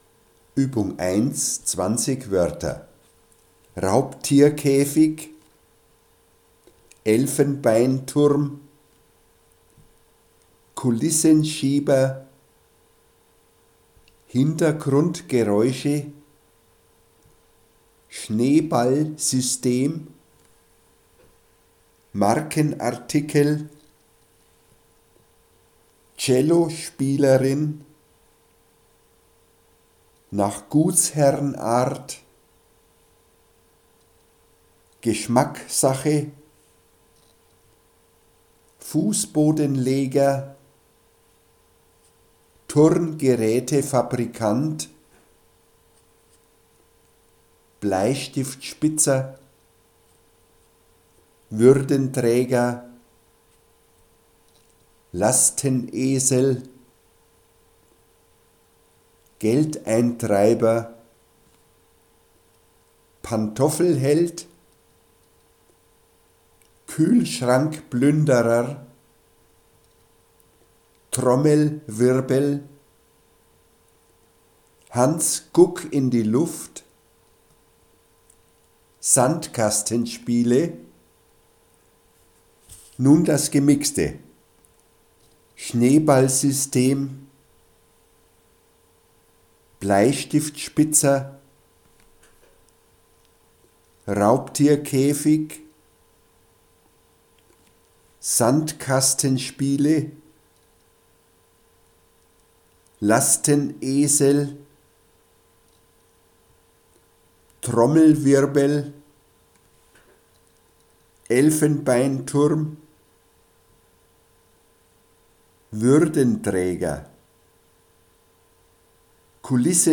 Gratis Übungsmaterial zum Hörtraining
Die Wortlisten könnten z.B. wie die Musterdatei "uebung-1-20-woertermix.mp3" als Audio aufgesprochen werden und dann zur Kontrolle beim Verstehen benutzt werden.
uebung-1-20-woertermix.mp3